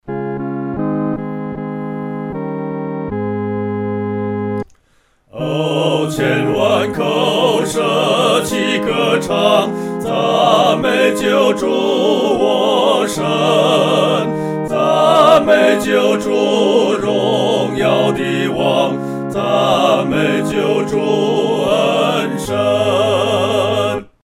合唱（四声部）
万口欢唱-合唱（四声部）.mp3